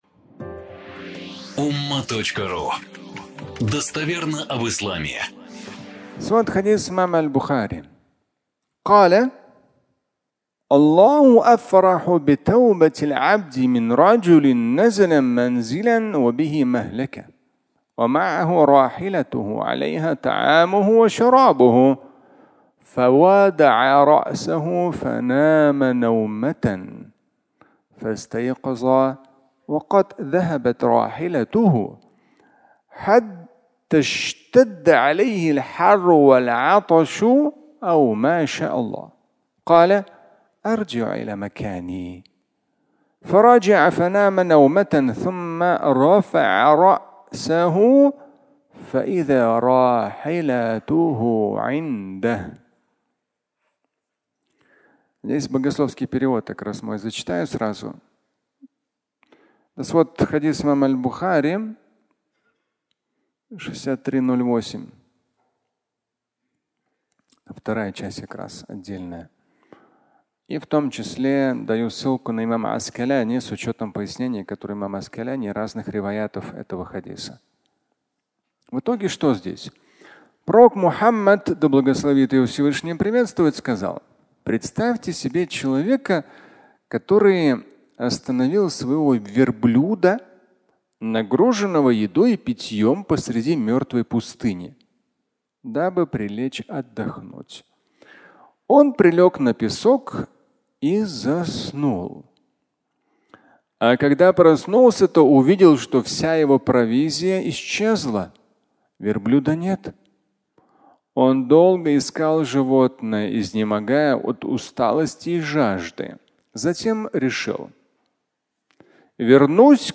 "Хадисы", 4-томник (аудиолекция)